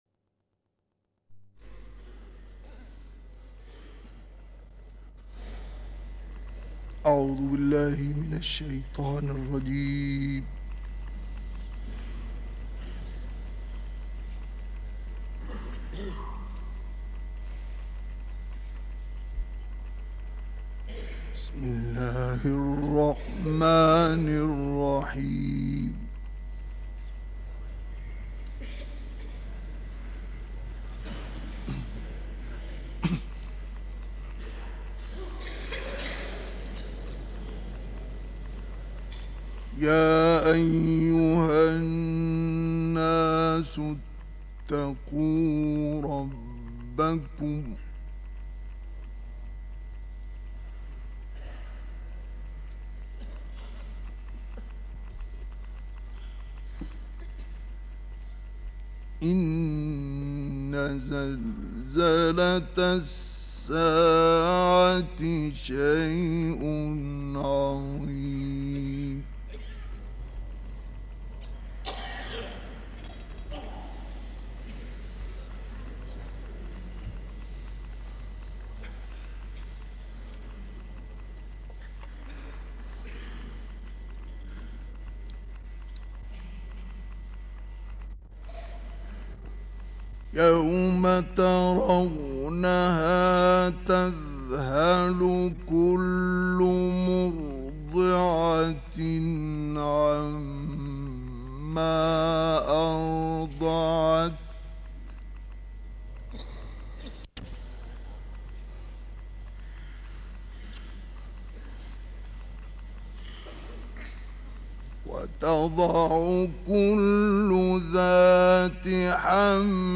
گروه چندرسانه‌ای ــ تلاوت آیات 1 تا 27 سوره مبارکه حج را با صدای شیخ مصطفی اسماعیل، قاری بنام مصری، می‌شنوید. این تلاوت در سال 1957 و در شهر حمص سوریه اجرا شده بود.